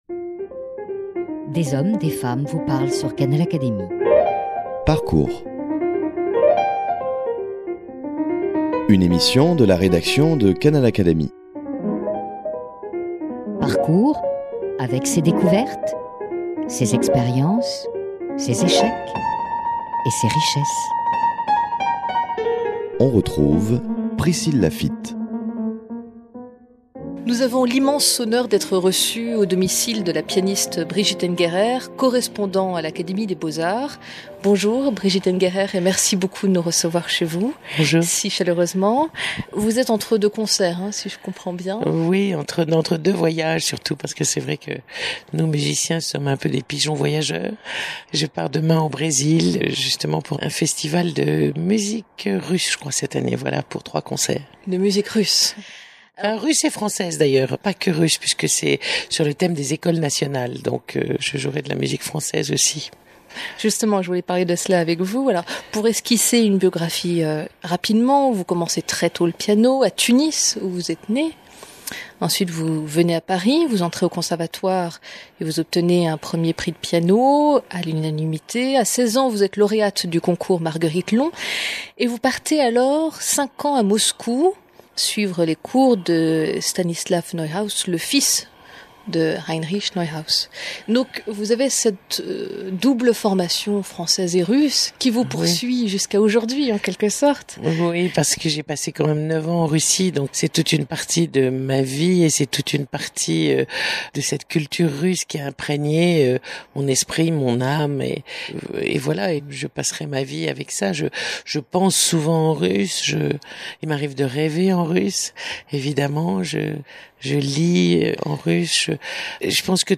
Elle nous a reçu chez elle, à Paris.